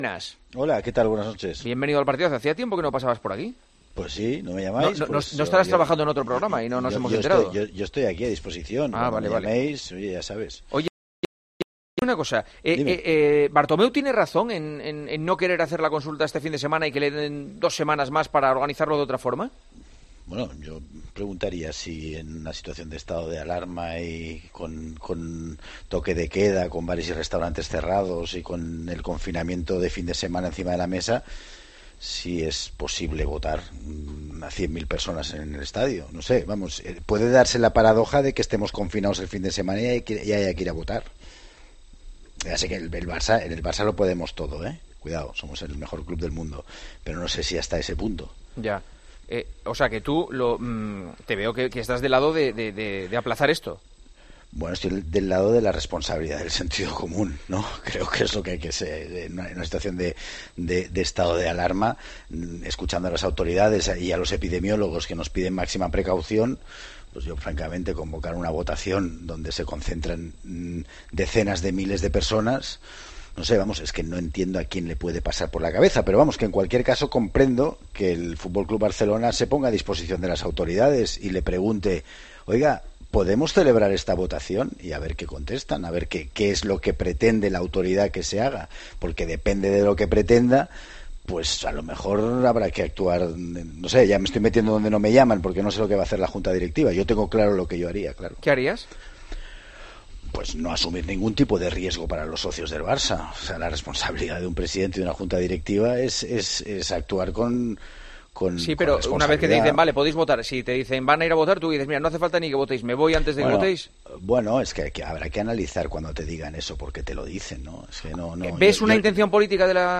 Escucha el debate sobre el referéndum del Barça en 'El Partidazo'